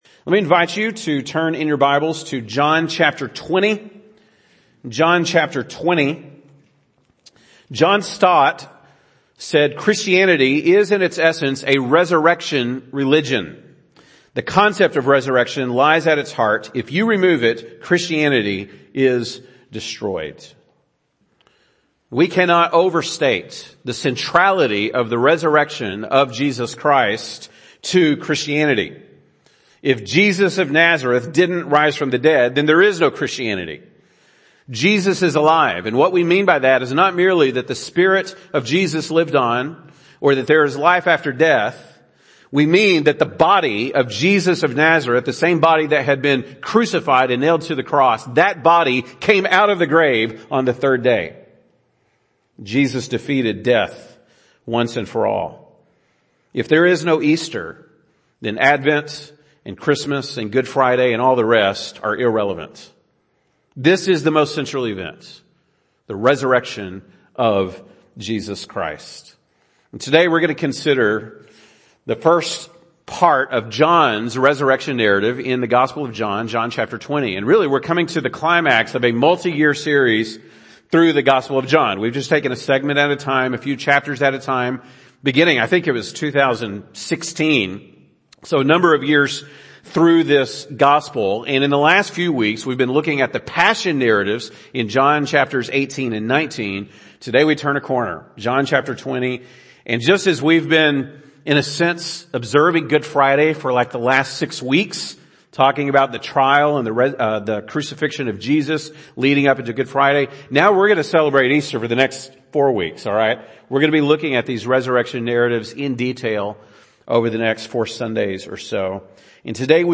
April 17, 2022 (Sunday Morning)